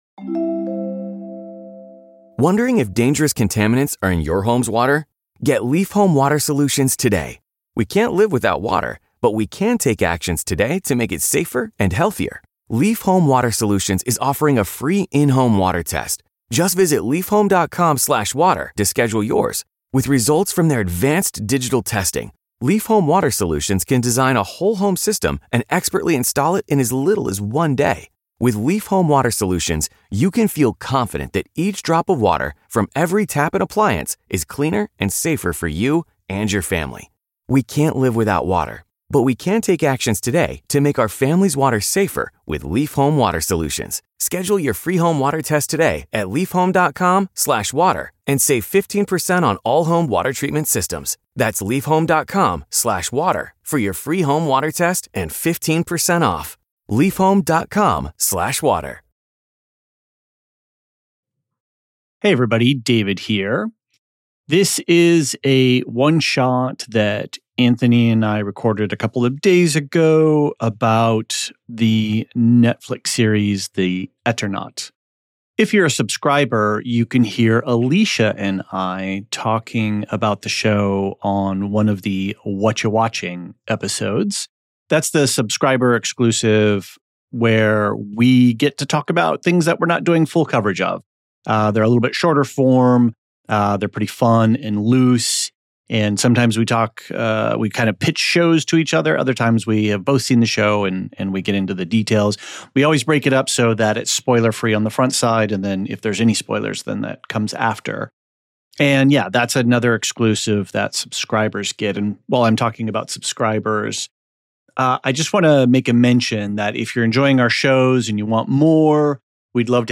We cover what's nominated, our predictions, what you need to know about each category to wow dinner party mates with fun facts, and of course interviews and voicemails going deeper – this time on Oscar snobbery, Godzilla love, Poor Things/Yorgos Lanthimos, the El Conde controversy, and the Sound nominees from the blind perspective. It's the last stuff you need to know before you fill in your Oscar ballot!